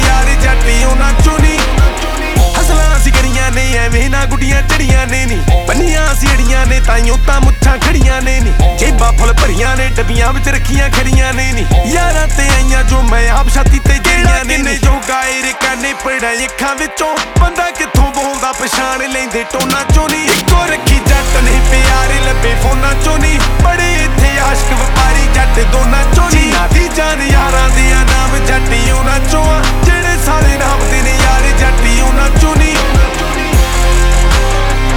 Жанр: Хип-Хоп / Рэп / Поп музыка